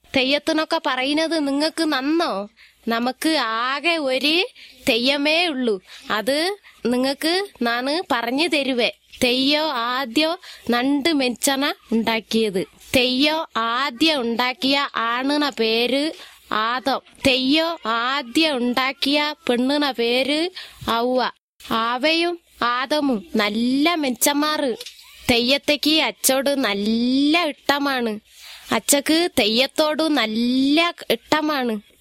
It seems to be (mildly) tonal, has a retroflex n, an unrounded back vowel, a prominent rolling r, an almost ejective-like dental affricate and possibly a syllabic/initial velar nasal as well.